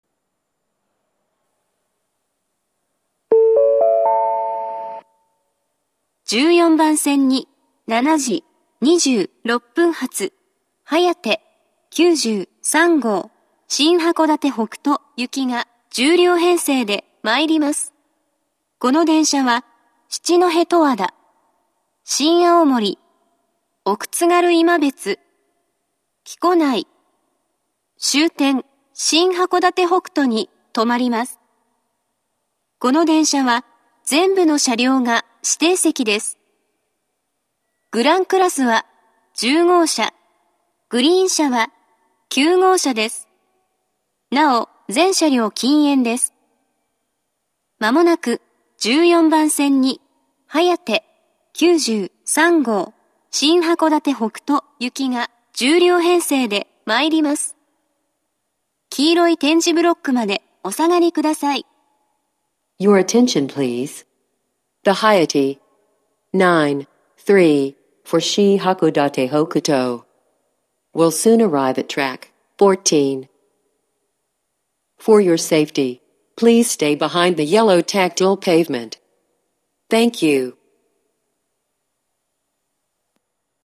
１４番線接近放送